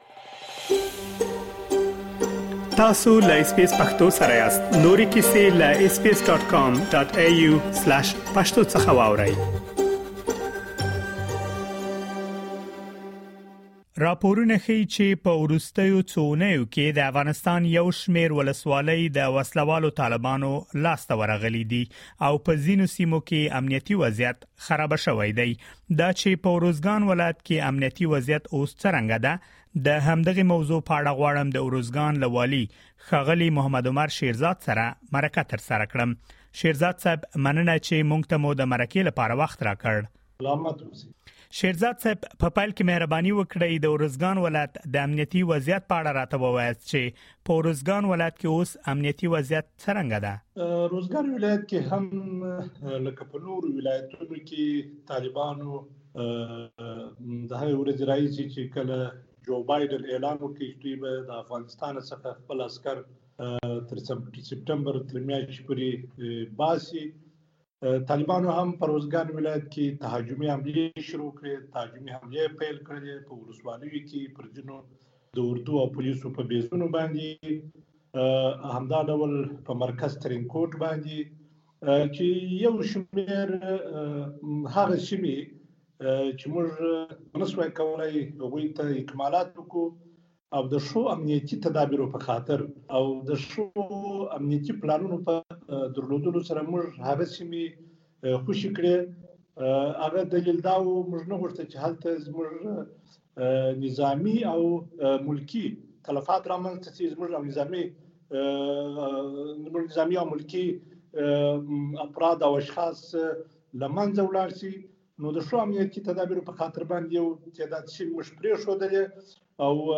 د اروزګان والي محمد عمر شېرزاد له اس بی اس پښتو سره په یوې ځانګړې مرکې کې ویلي چې د آسټرالیایي ځواکونو له وتلو وروسته دغه ولایت په ځینو برخو کې پرمختګ کړی او په ځینو برخو کې شاتګ شوی دی. نوموړی زیاتوي چې اوسمهال طالبان هڅه کوي ترڅو د ارزوګان مرکز ترینګوټ ونیسي، ولې د دې هېڅ امکانات نشته ترڅو دوي دغې موخې ته ورسېږي.